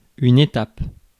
Ääntäminen
IPA: /e.tap/